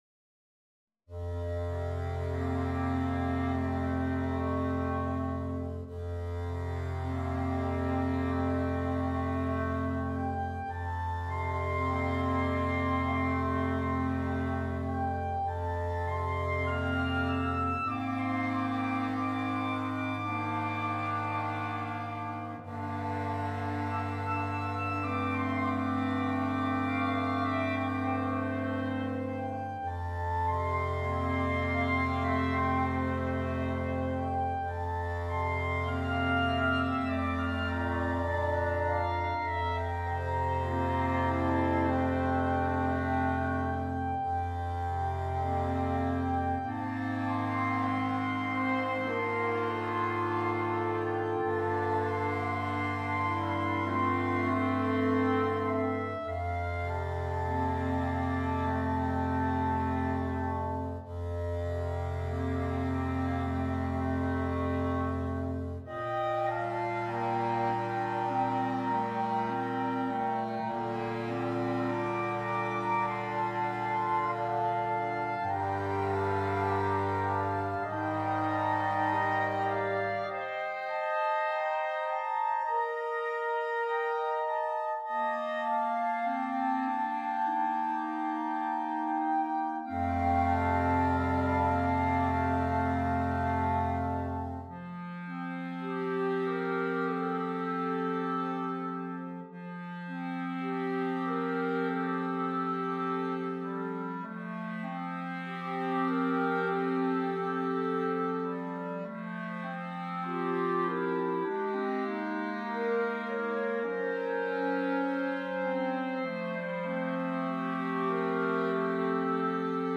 slow, rich and flowing serenade for Clarinet Septet.